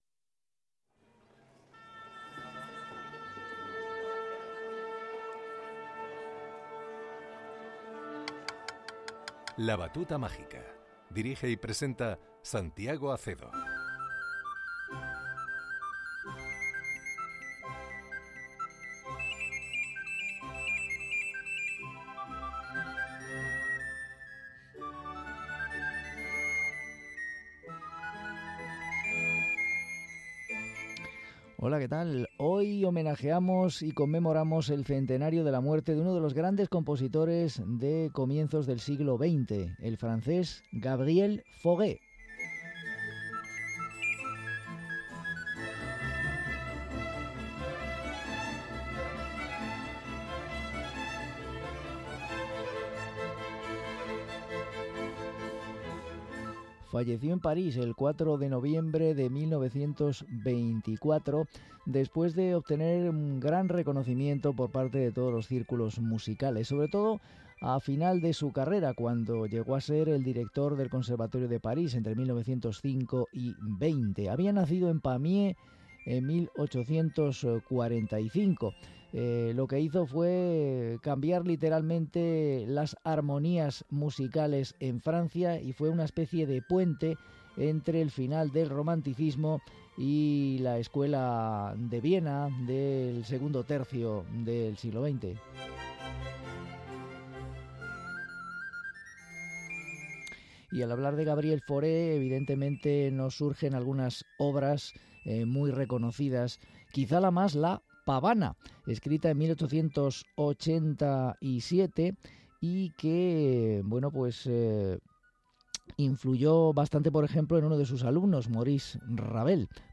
versión con Coro
versión para Violonchelo y Orquesta
versión para Flauta y Orquesta